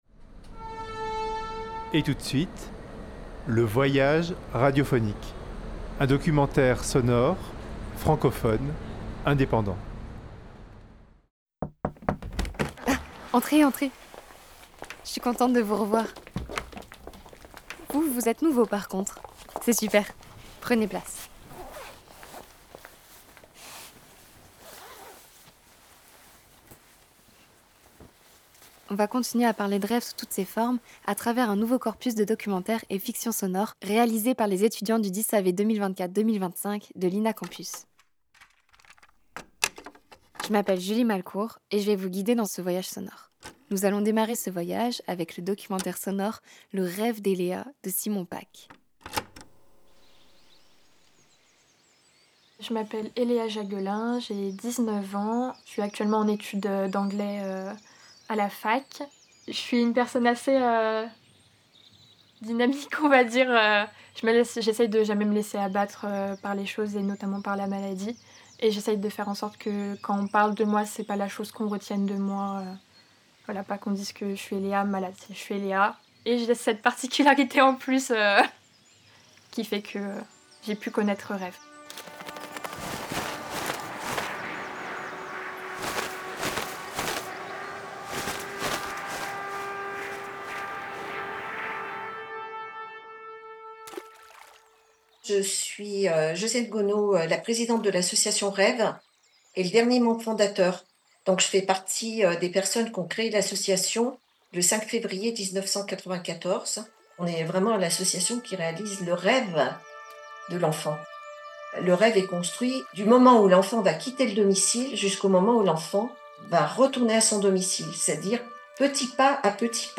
RÊVONS Une revue sonore collective France – 2025 Vous voilà entré dans le salon sonore du rêve.
Vous voilà à la foire du trone !